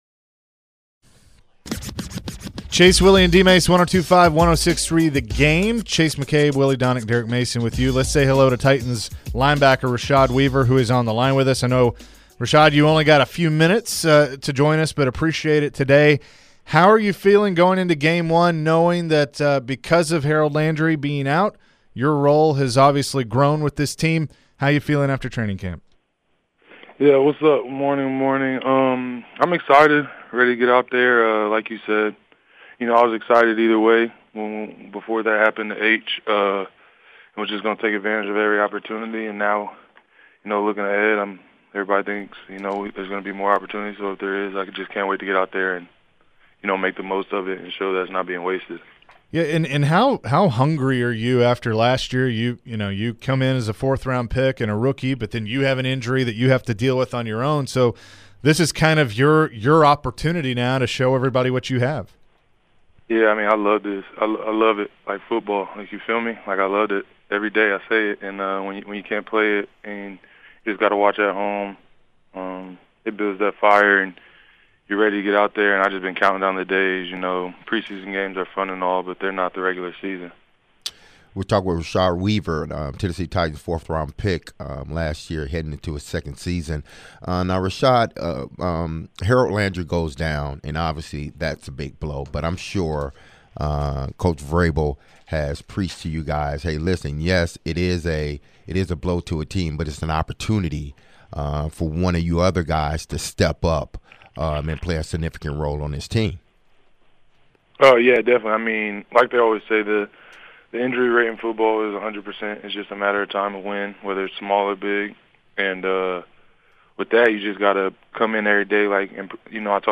Rashad Weaver Full Interview (09-08-22)